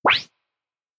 sound-button-click.cad3ef43.mp3